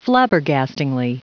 Prononciation du mot flabbergastingly en anglais (fichier audio)
Prononciation du mot : flabbergastingly